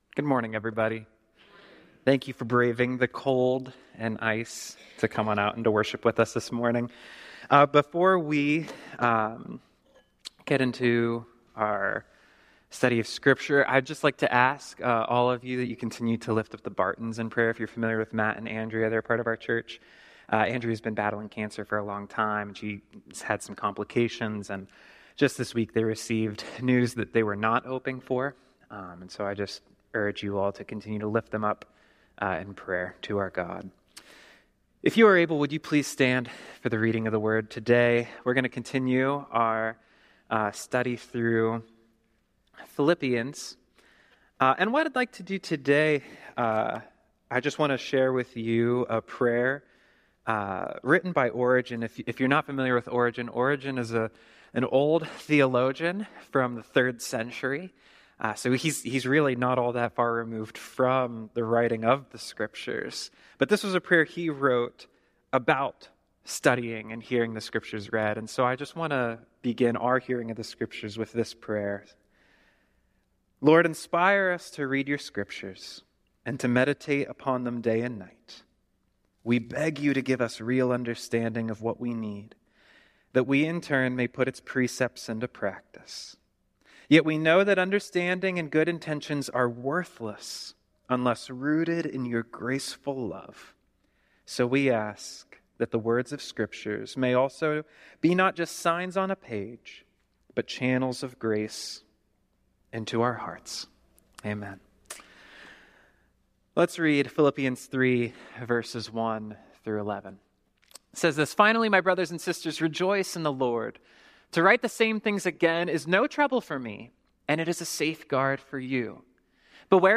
Sermons - First Church
Weekly messages from First Church of Christ in Altoona, PA